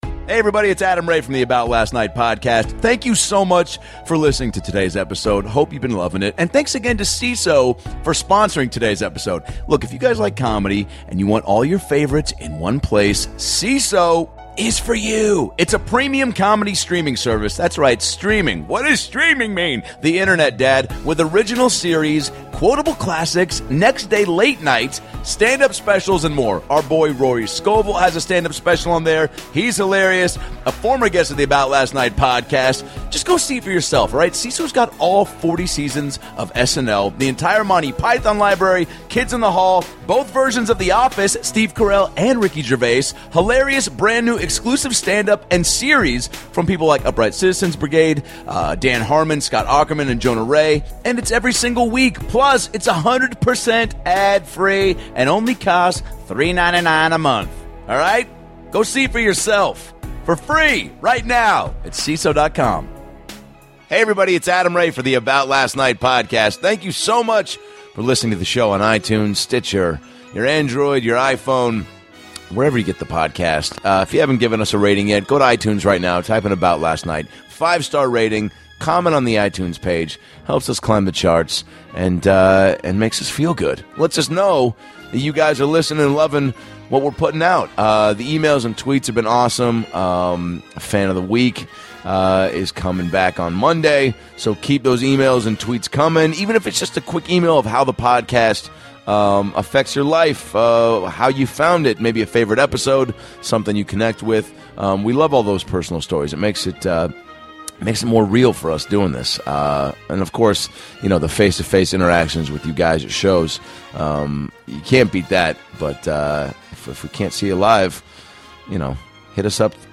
An amazing interview with lots of laughs.